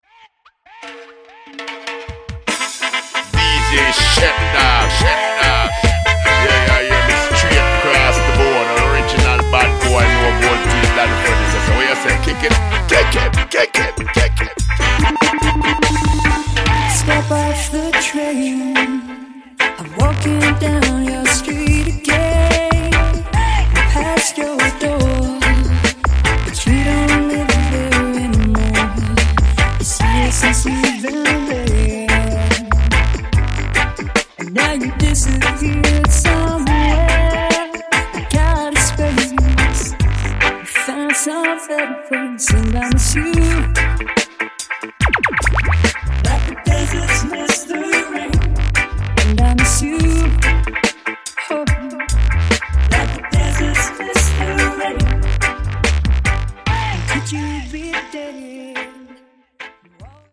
heavy hitting